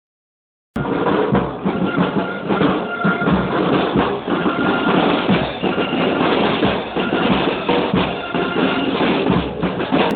E' domenica, andiamo a vedere e siamo fortunati, perchè oggi c'è anche una grande sfilata per la giornata del diabete.
Poi un momento di panico: le truci allieve di una scuola di suore domenicane, non contente della banda militare, suonano in proprio
tamburi.mp3